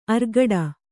♪ argaḍa